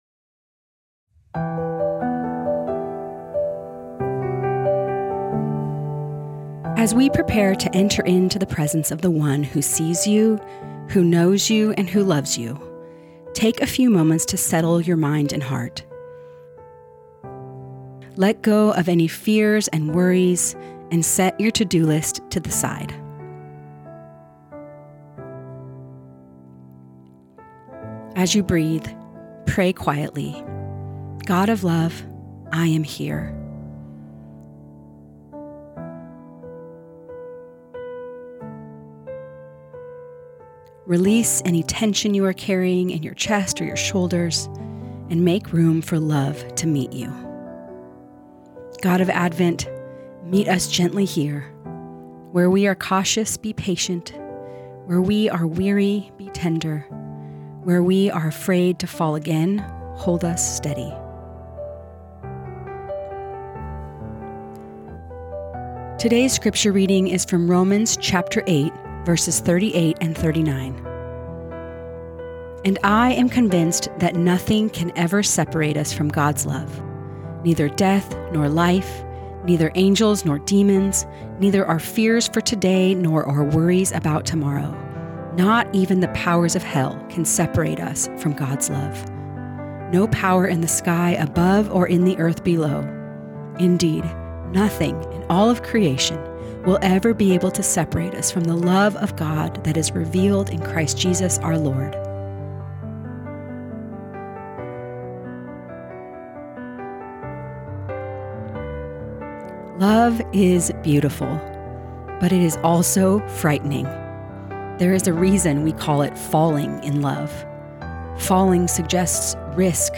Guided Listening Practice